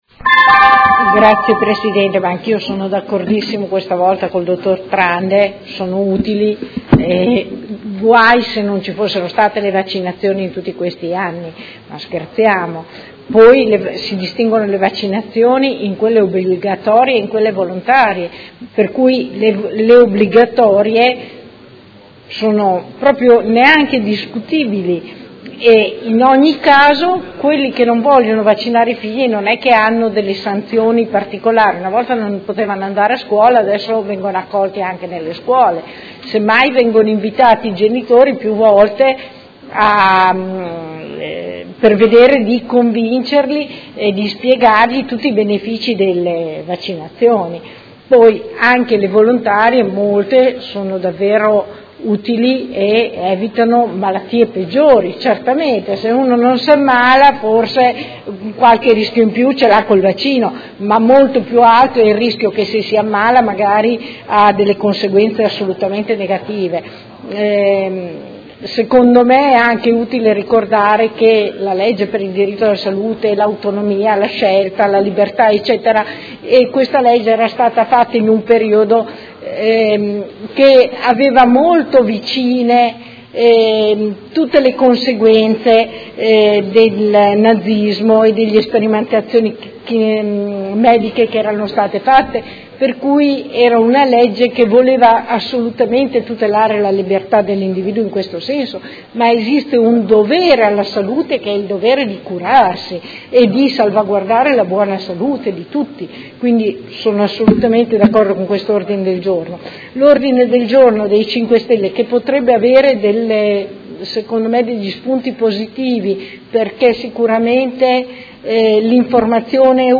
Seduta del 14/01/2016. Dibattito su Mozione ed Emendamento riguardanti la copertura vaccinale della popolazione modenese e Ordine del Giorno riguardante le vaccinazioni pediatriche